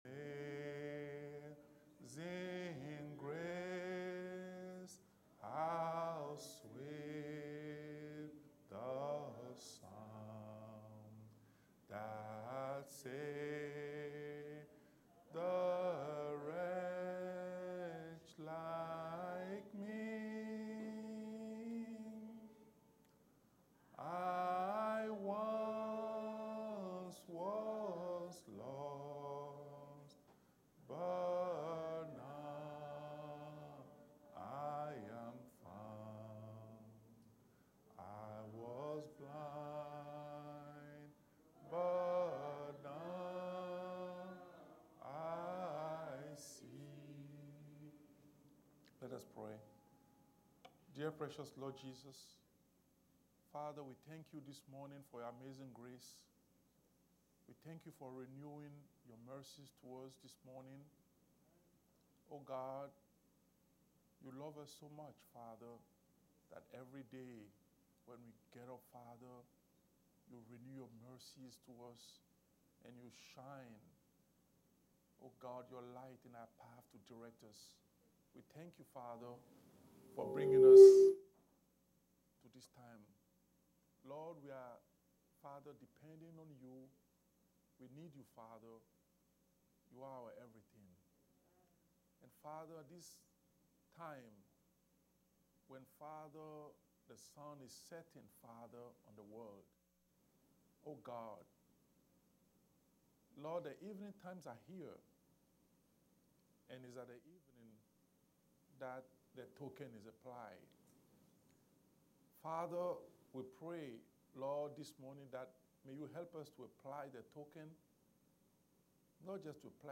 Series: Sunday school